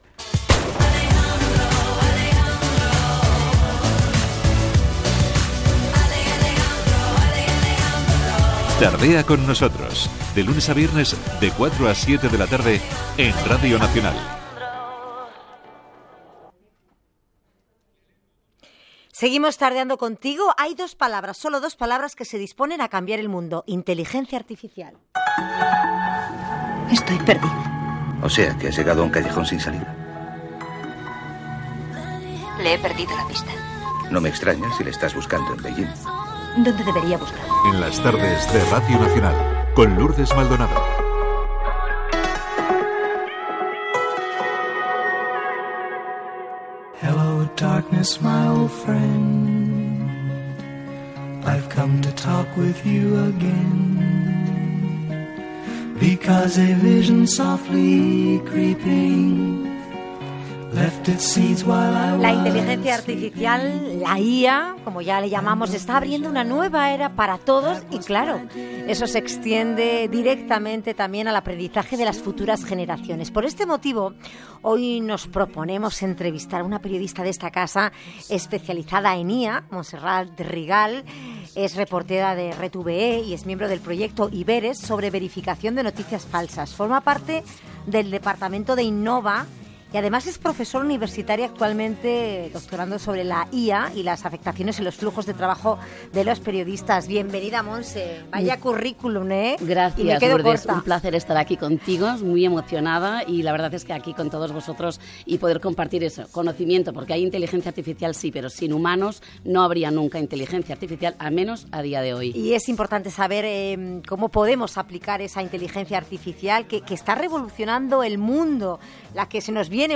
Identificació del programa, entrevista